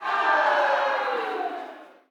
bl_crowd_ohno.ogg